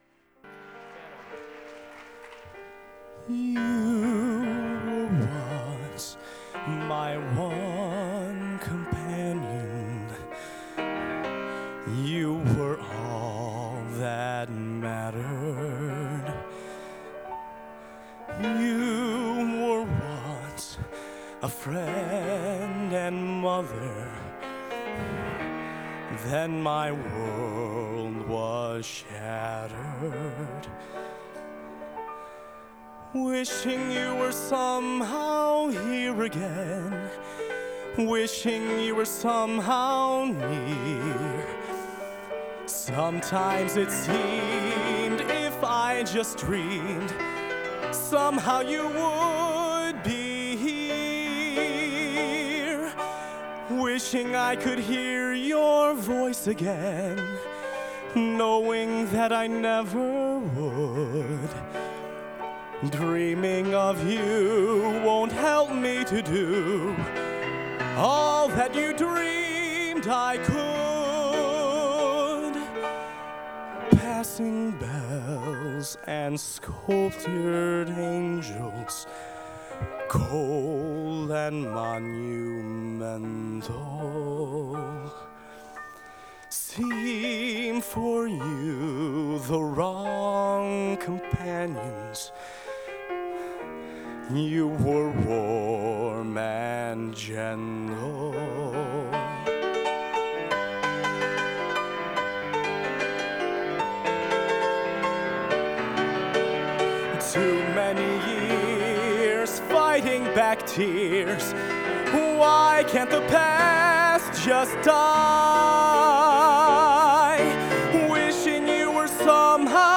Genre: Broadway | Type: Solo